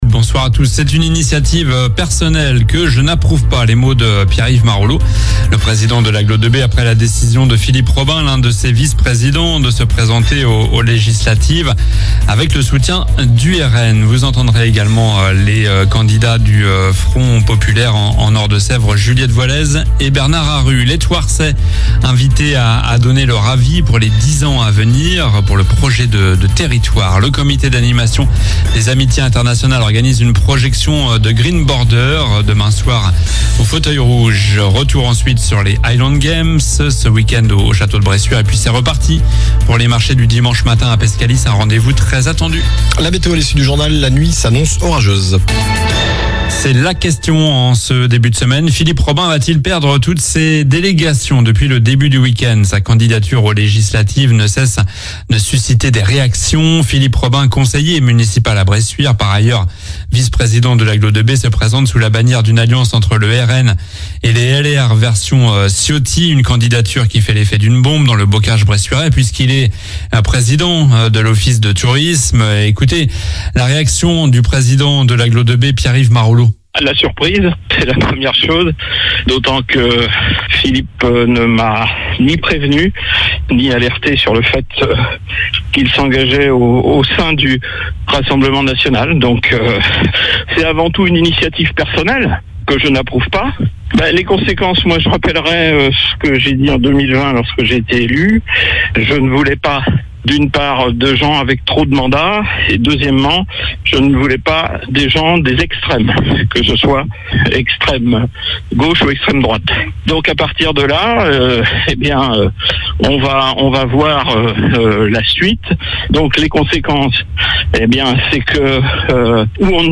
Journal du lundi 17 juin (soir)